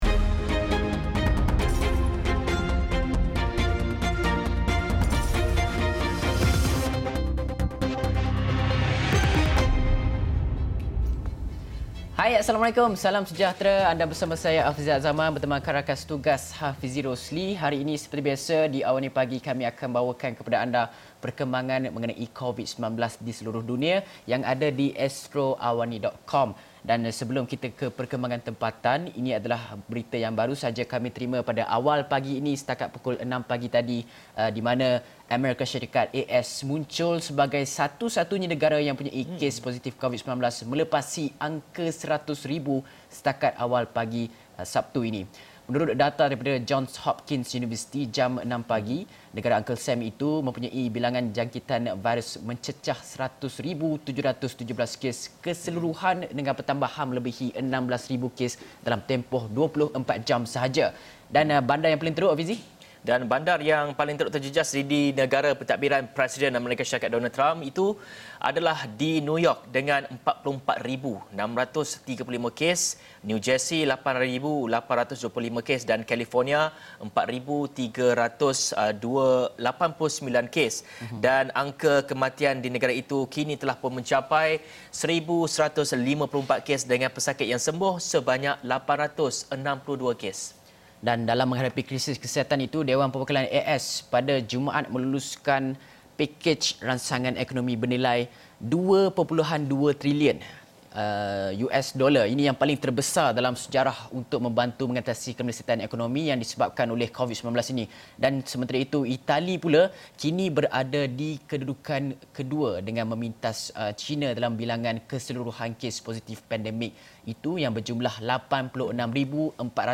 Berita kemaskini COVID-19 [28 Mac 2020]